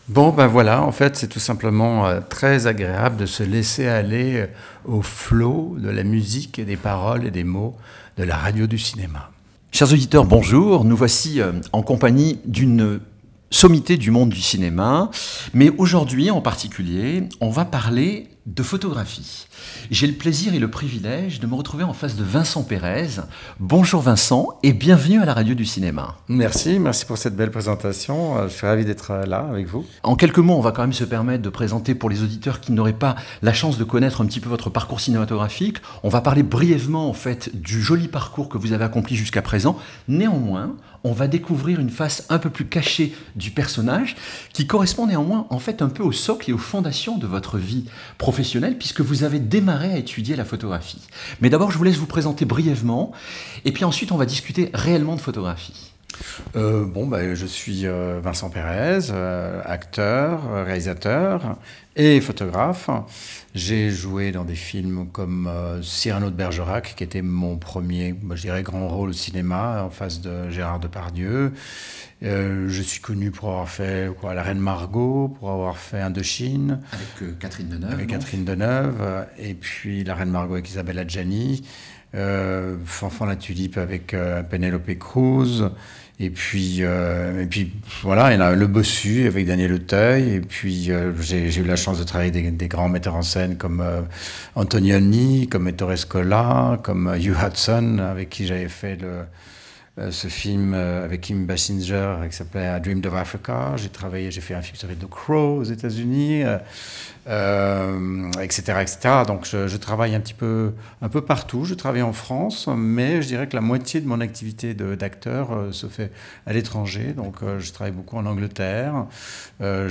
%%Les podcasts, interviews, critiques, chroniques de la RADIO DU CINEMA%%